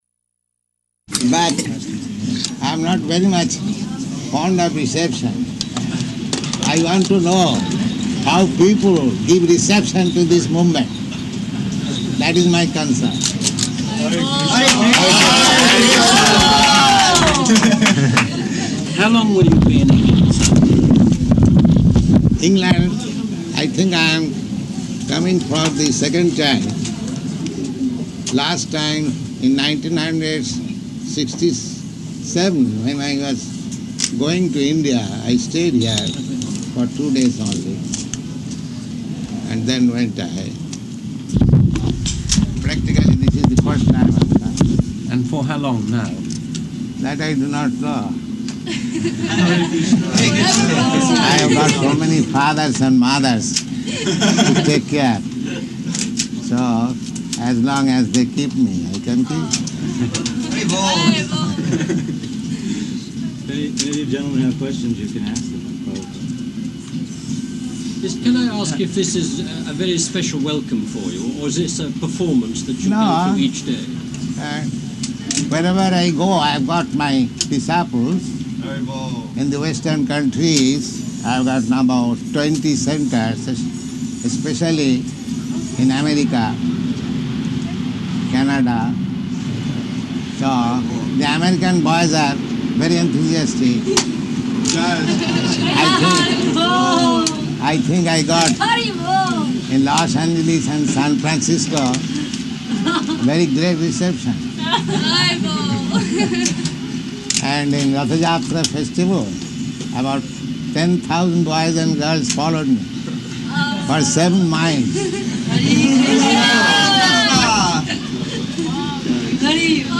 Arrival Address --:-- --:-- Type: Lectures and Addresses Dated: September 11th 1969 Location: London Audio file: 690911AR-LONDON.mp3 Prabhupāda: ...but I'm not very much fond of reception.